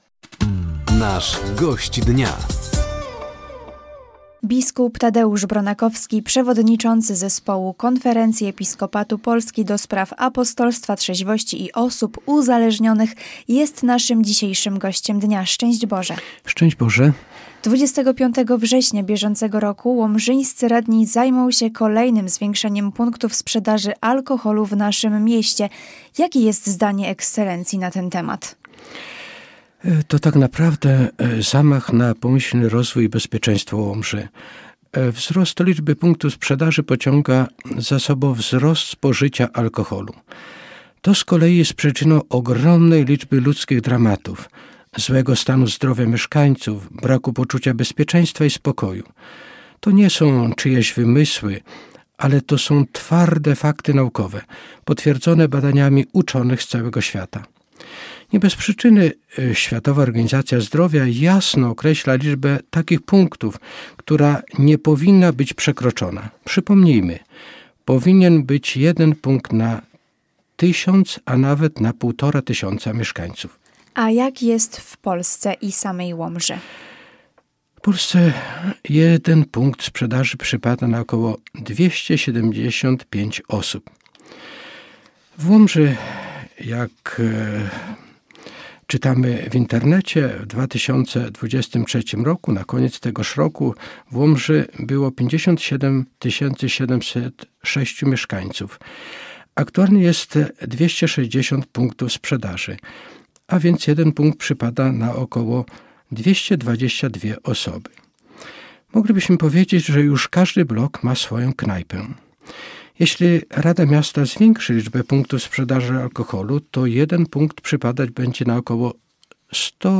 Zbliżające się głosowanie Łomżyńskich Radnych w sprawie zwiększenia sprzedaży alkoholu w mieście – to główny temat rozmowy podczas audycji ,,Gość Dnia”  z biskupem Tadeuszem Bronakowskim, przewodniczącym Konferencji Episkopatu Polski ds. Apostolstwa Trzeźwości i Osób Uzależnionych.